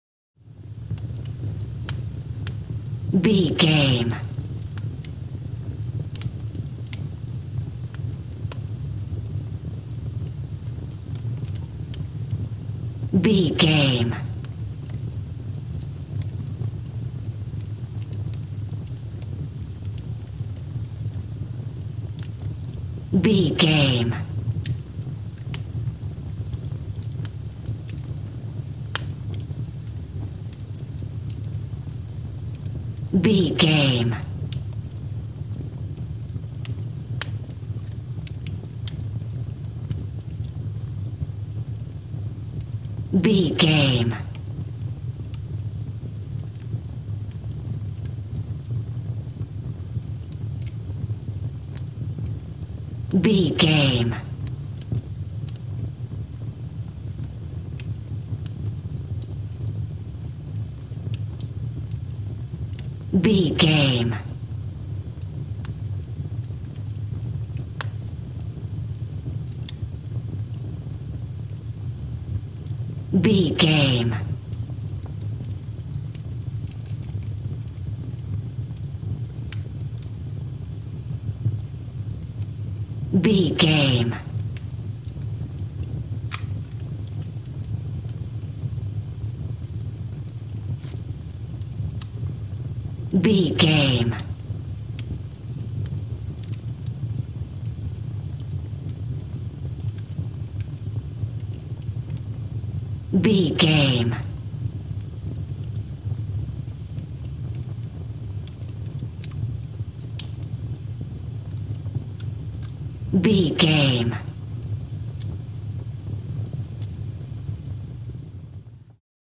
Fire with crakle
Sound Effects
torch
fireplace